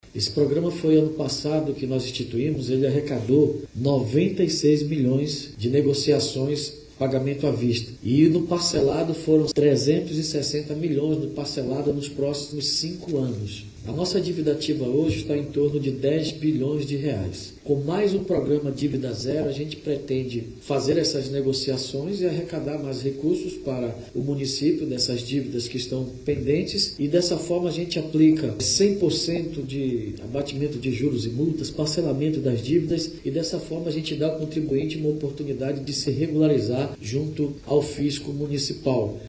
O anúncio foi feito em coletiva de imprensa, nesta quinta-feira (27), pelo prefeito de Manaus, David Almeida.
SONORA01_PREFEITO.mp3